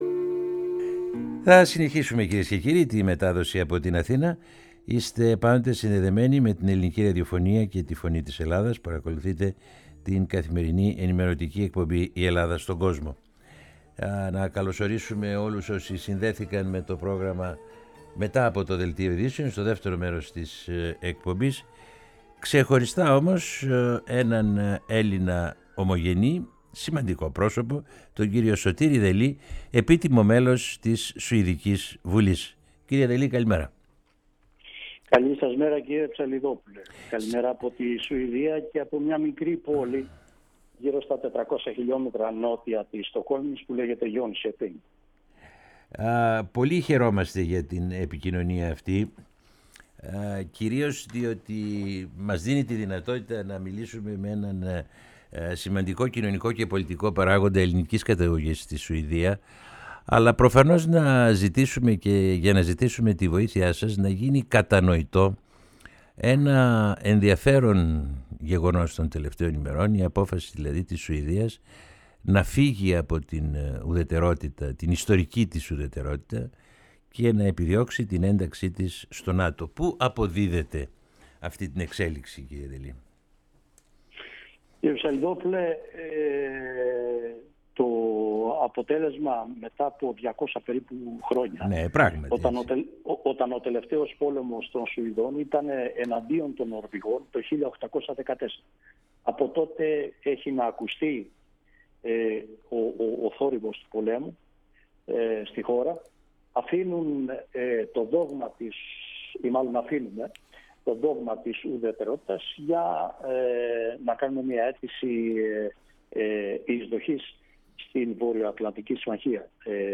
Ακούστε ολόκληρη τη συνέντευξη του κ. Δελή στο ηχητικό της ανάρτησης.